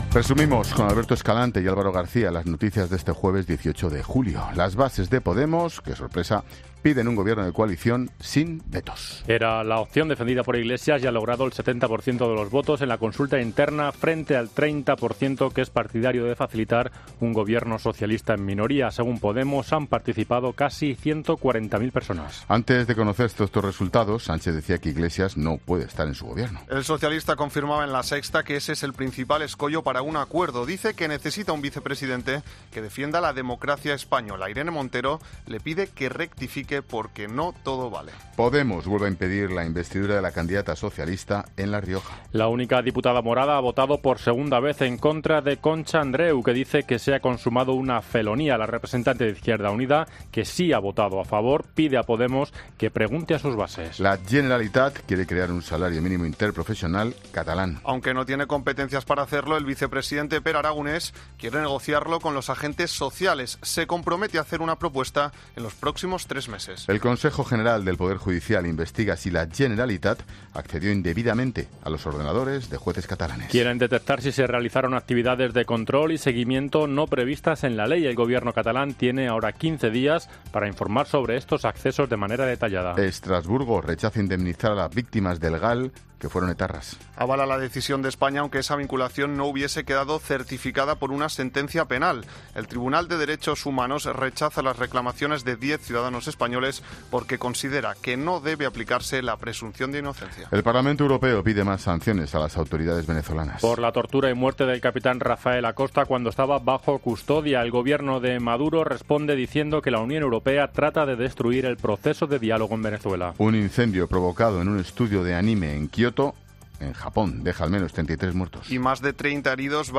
Boletín de noticias de COPE del 18 de julio de 2019 a las 20.00 horas